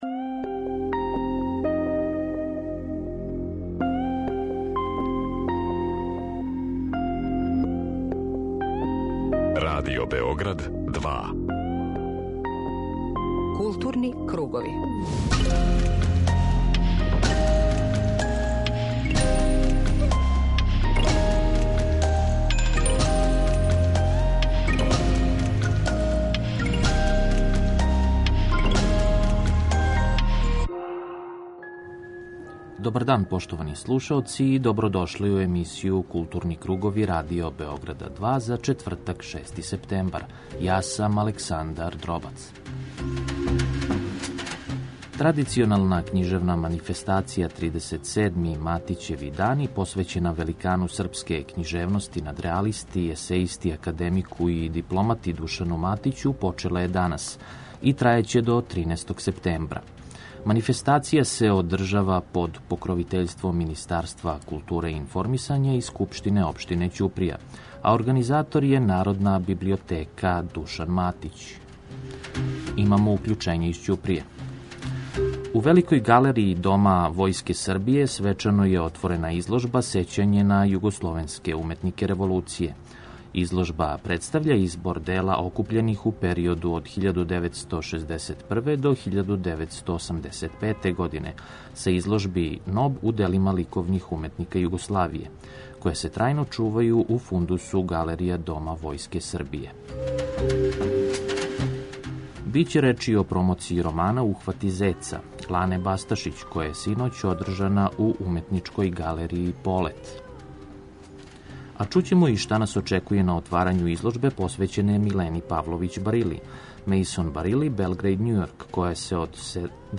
Дневни магазин културе